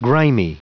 Prononciation du mot grimy en anglais (fichier audio)
Prononciation du mot : grimy